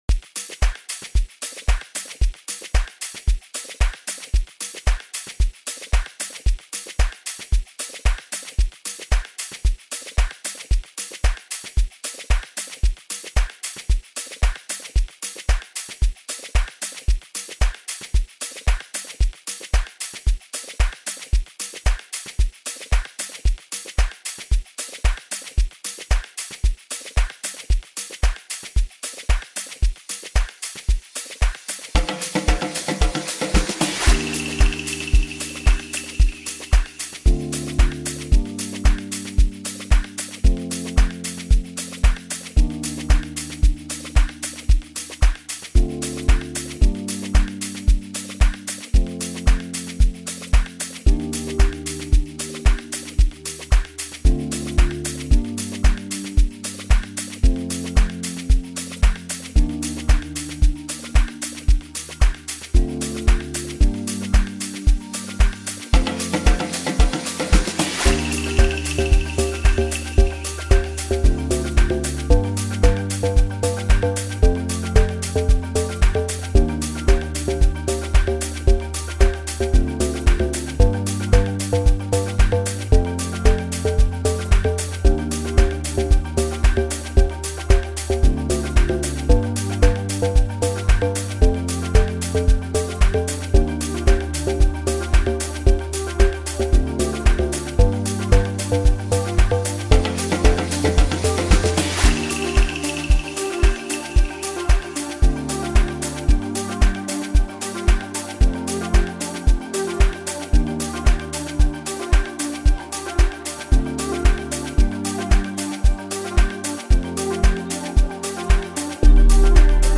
Home » Amapiano » DJ Mix
is an elegantly arranged piece